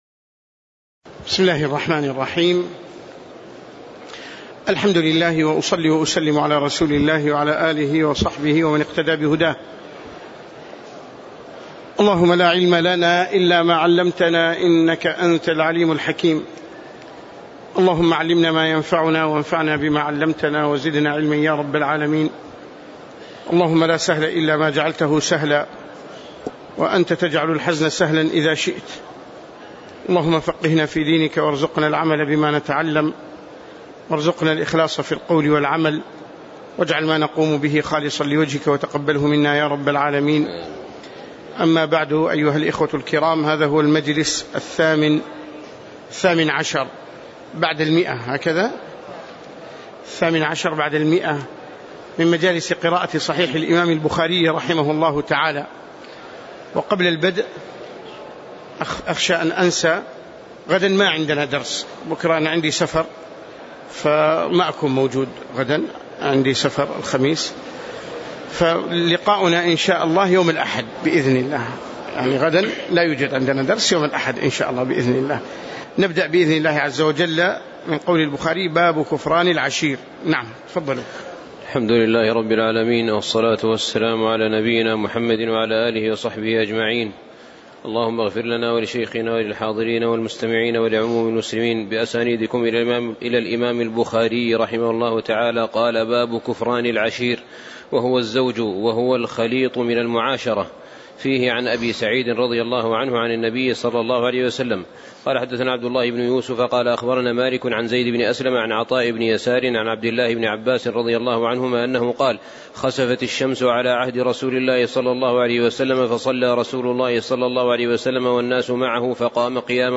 تاريخ النشر ١٤ شعبان ١٤٣٨ هـ المكان: المسجد النبوي الشيخ